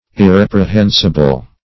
Search Result for " irreprehensible" : The Collaborative International Dictionary of English v.0.48: Irreprehensible \Ir*rep`re*hen"si*ble\, a. [L. irreprehensibilis: cf. F. irr['e]pr['e]hensible.